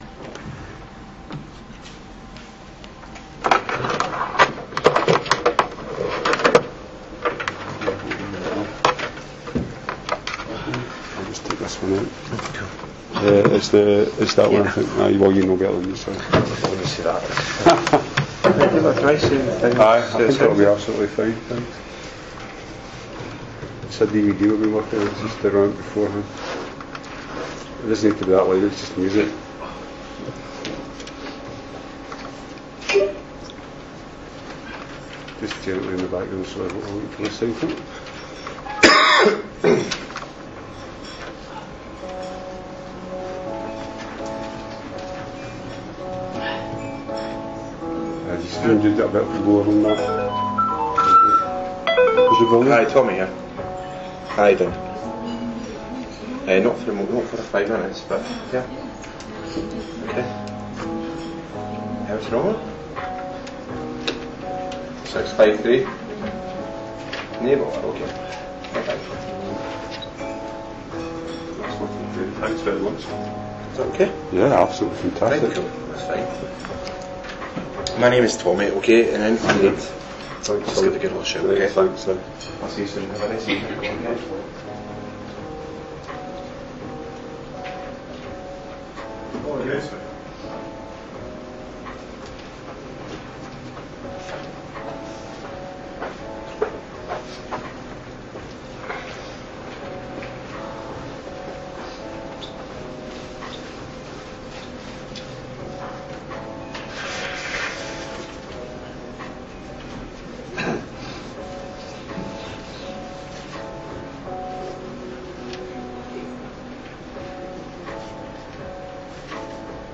Seminar Series 5: Lecture 2